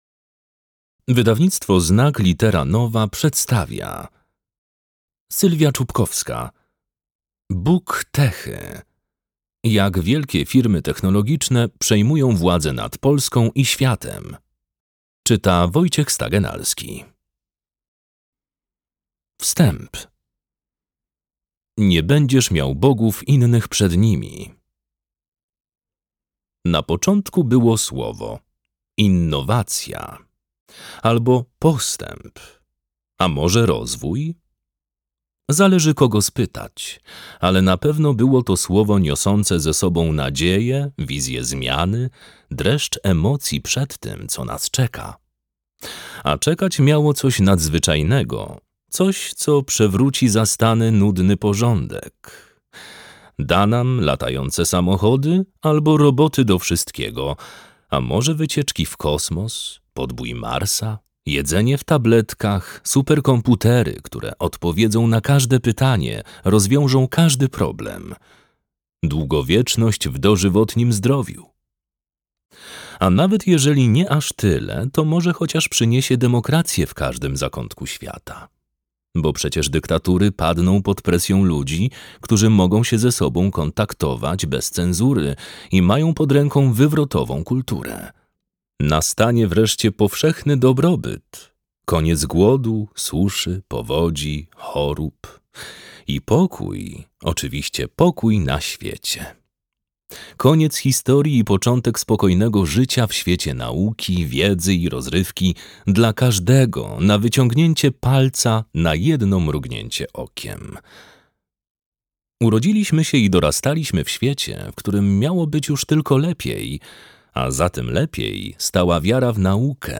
Jak wielkie firmy technologiczne przejmują władzę nad Polską i światem - Sylwia Czubkowska - audiobook - Legimi online